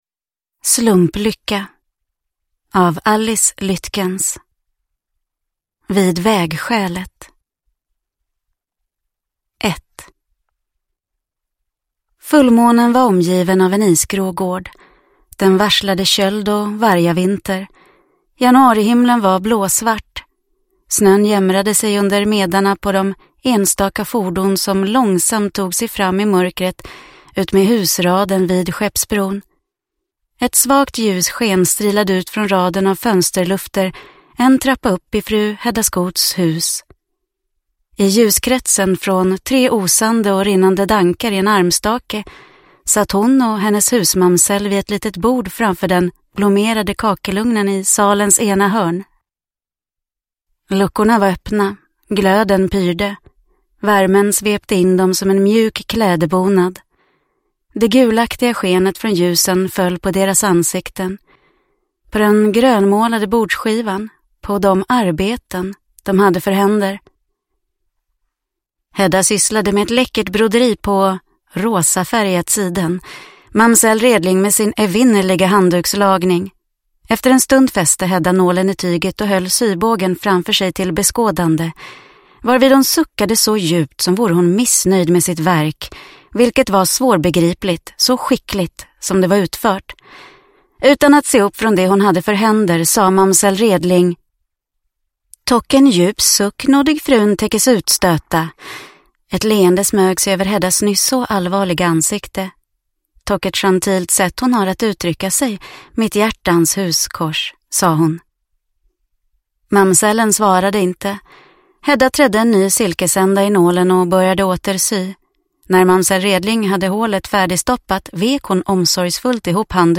Slumplycka – Ljudbok – Laddas ner